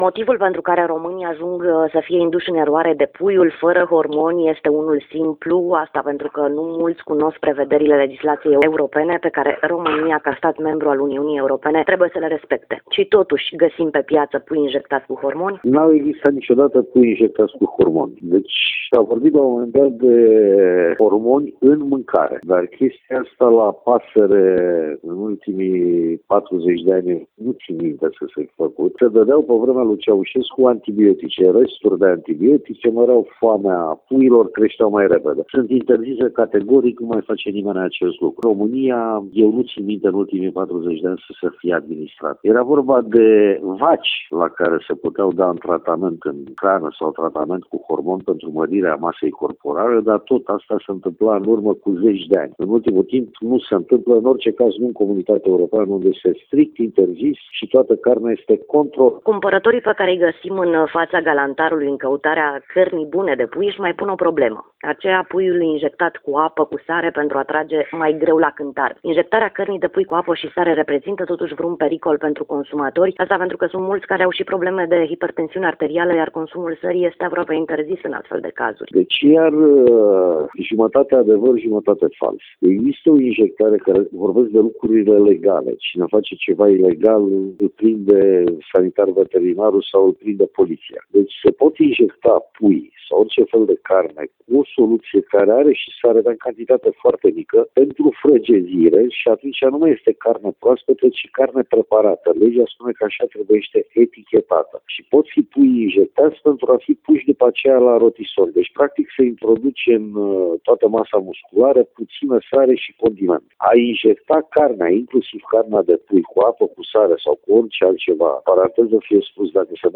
Interviuri